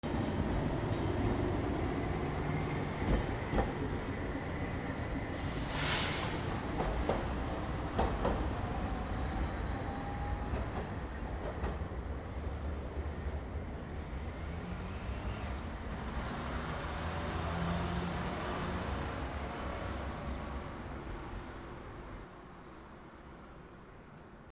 路面電車 15
/ E｜乗り物 / E-65 ｜路面電車 / 再構成用